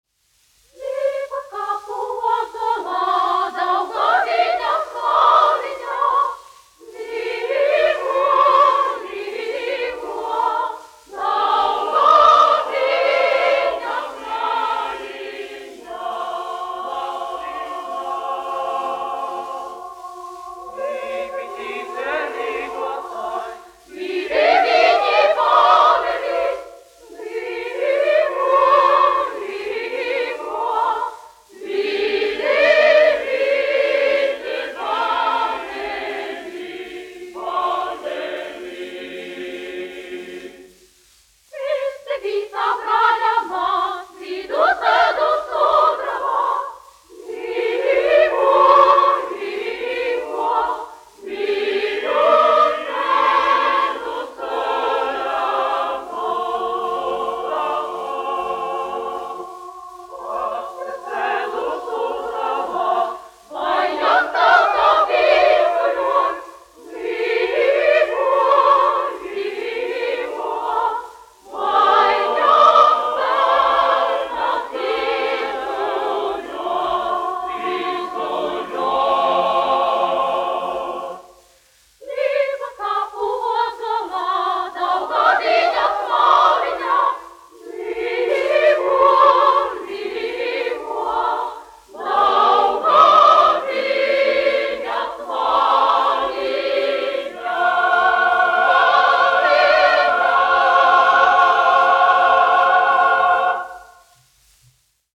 Andrejs Jurjāns, 1856-1922, aranžētājs
Latvijas Radio koris, izpildītājs
1 skpl. : analogs, 78 apgr/min, mono ; 25 cm
Latviešu tautasdziesmas
Latvijas vēsturiskie šellaka skaņuplašu ieraksti (Kolekcija)